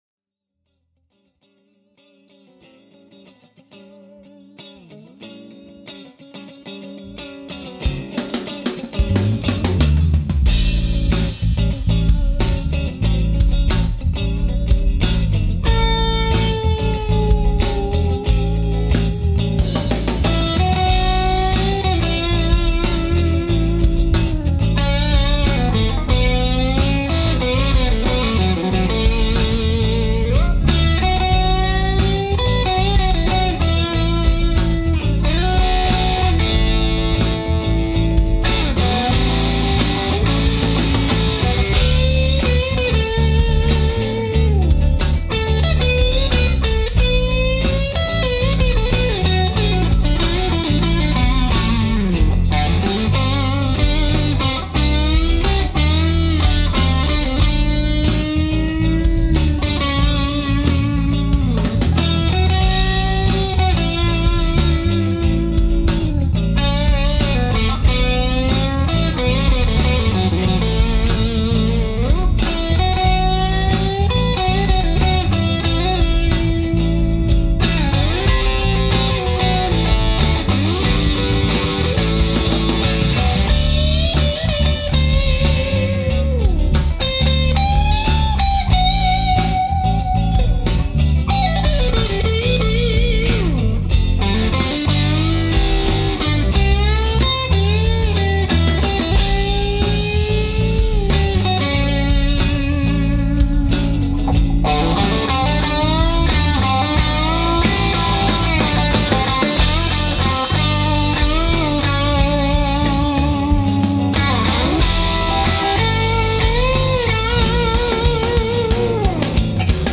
Well, as these things go, I ended up re-recording the entire song using just the ’59 – it sounded so freakin’ fantastic.
The verses are played in the neck position of the ’59, while the refrains are played in the bridge. The contrast between the two pickups is incredible. I had the amp absolutely cranked, but because the pups are not really high gain, they drive the amp nicely, but maintain a real smooth texture.